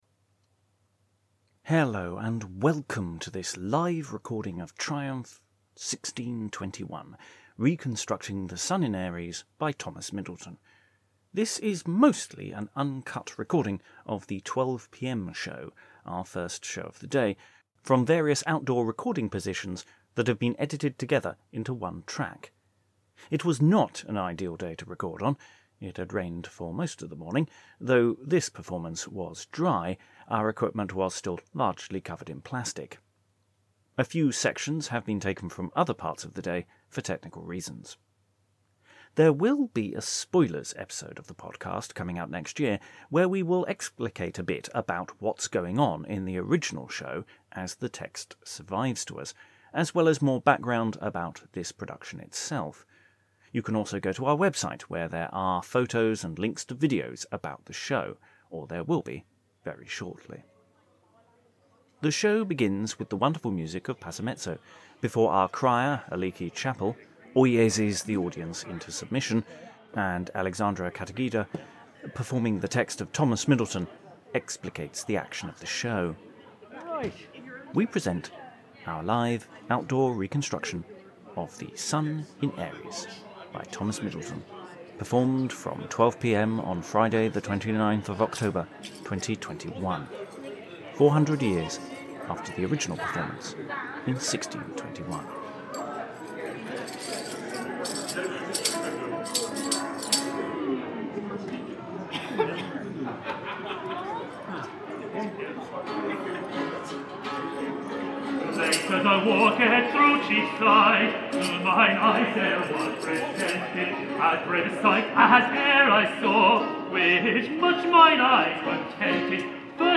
Live reconstruction of The Sun in Aries by Thomas Middleton - the 1621 Lord Mayor's Show
This is mostly an uncut recording of the 12pm show, our first of the day, from various outdoor recording positions that have been edited together into one track. It was not an ideal day to record on - it had rained for most of the morning and, though this performance was dry, our equipment was still largely covered in plastic. A few sections have been taken from other parts of the day for technical reasons.